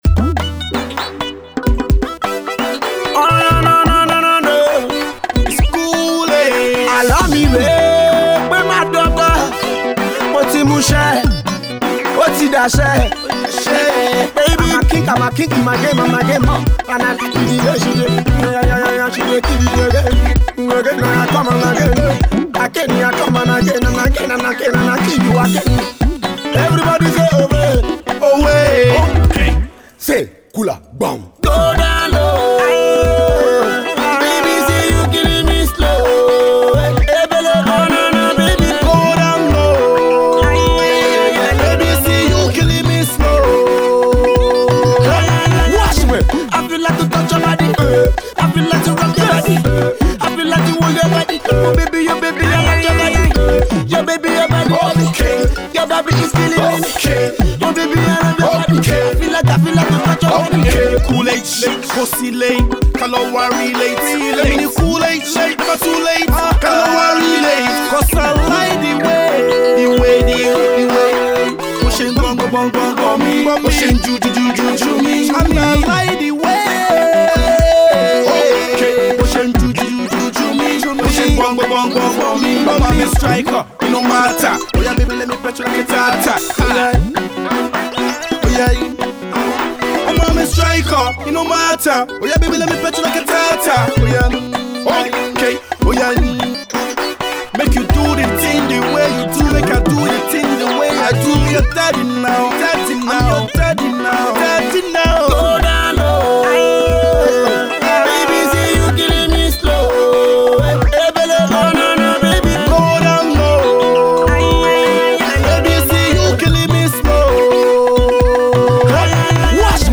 Pop banger